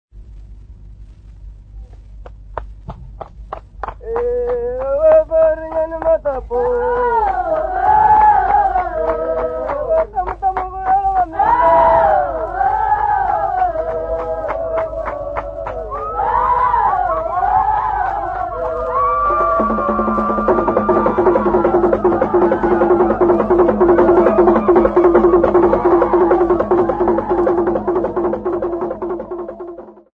Group of Tonga men and women at Guiguni
Folk Music
Field recordings
Africa Mozambique city not specified f-mz
Indigenous music